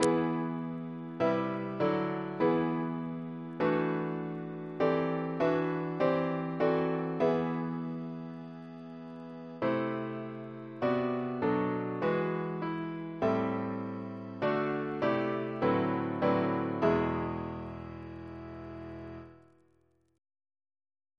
Double chant in Edesc Composer